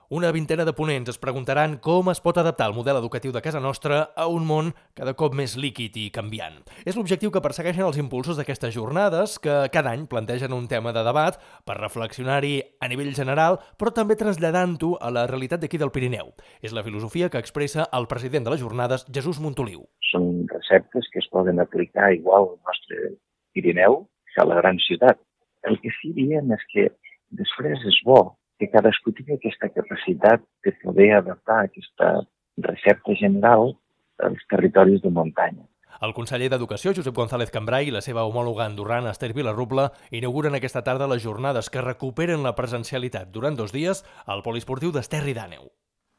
Notícia amb declaracions